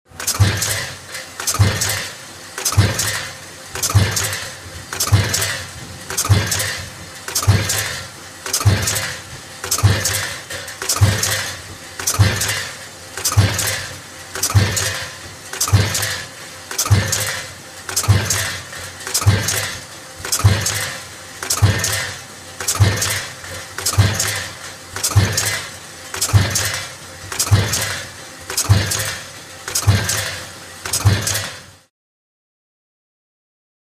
Factory, Punch Press
Slow Impacts Of Punch Press Running With Air Release.